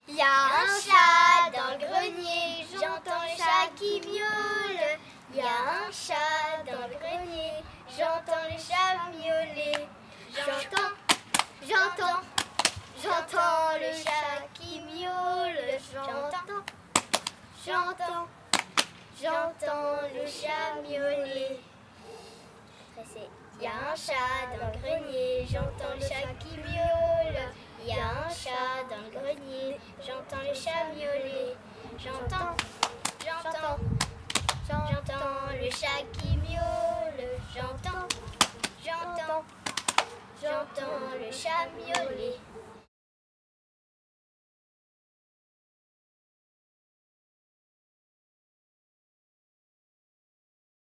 Genre : chant
Type : chant de mouvement de jeunesse
Interprète(s) : Les Scouts de Gilly
Lieu d'enregistrement : Gilly